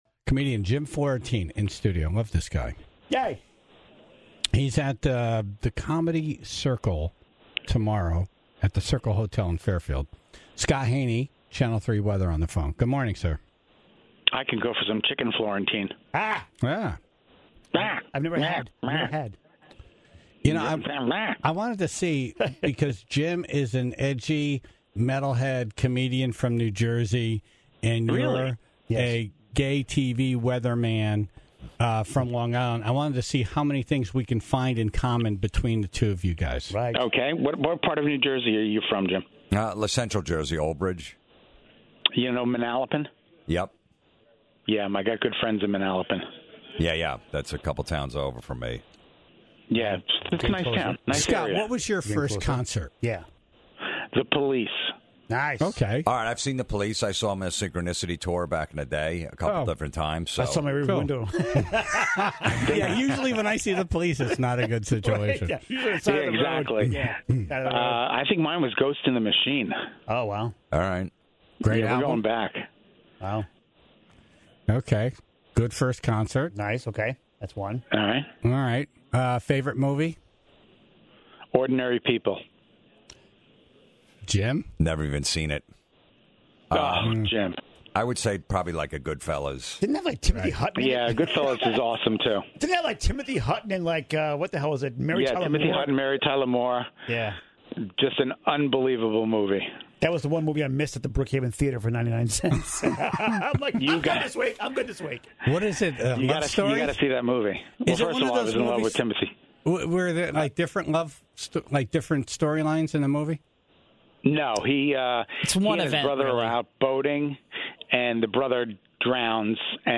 Comedian Jim Florentine was in studio today, and is big metalhead and hard rock fan.